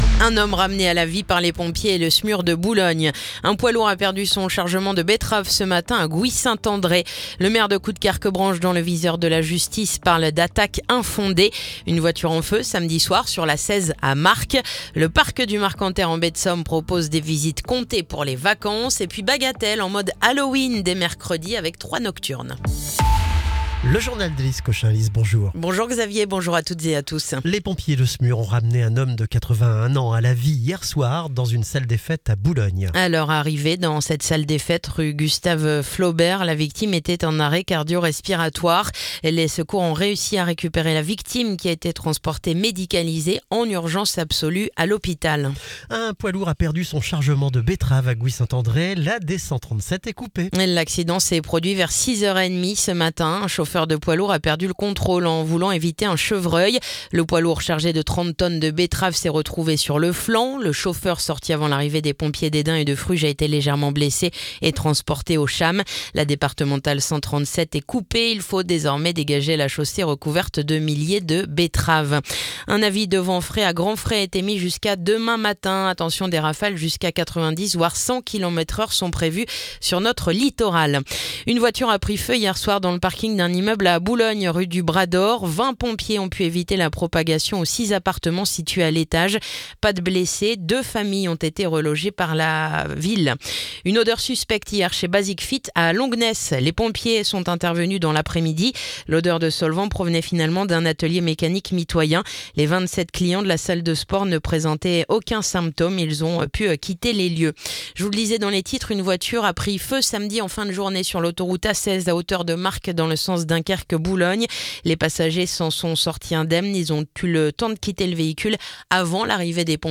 Le journal du lundi 20 octobre